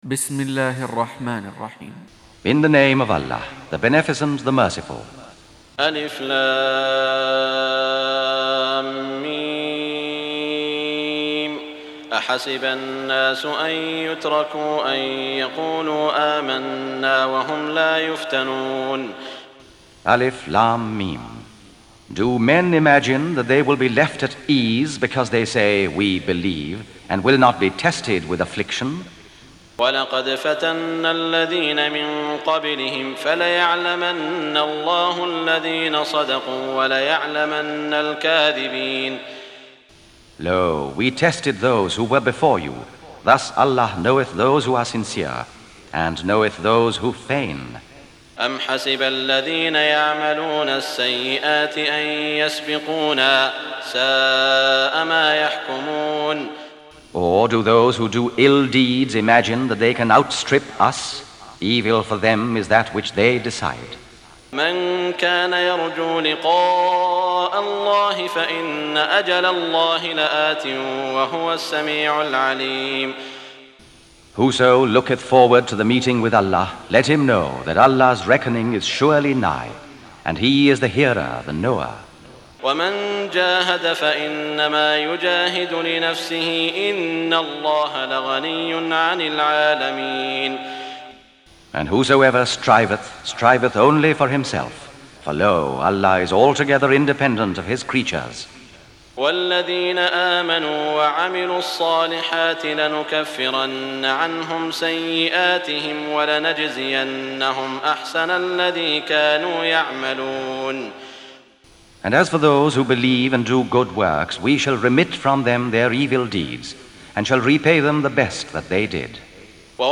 Recitation
With Qaris Sudais And Shuraym